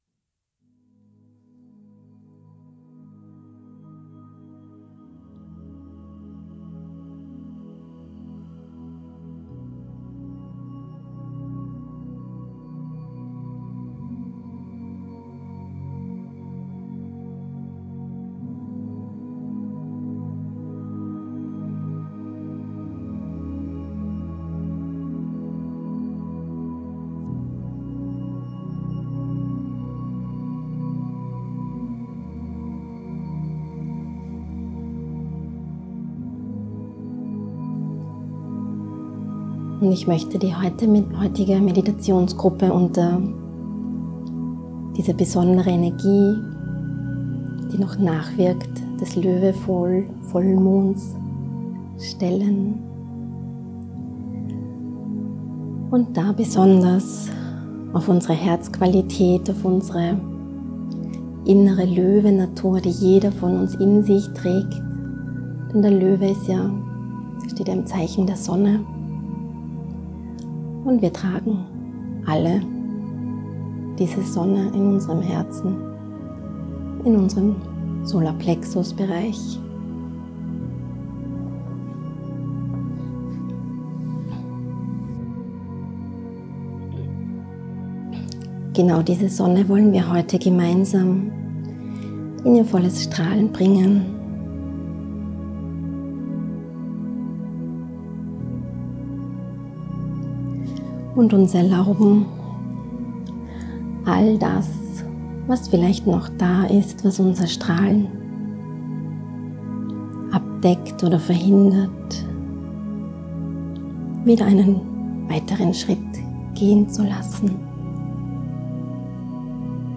GEFÜHRTE MEDITATION ALS MP3
1. Erklärung zur Meditationsgruppe (4 Min ohne Musik)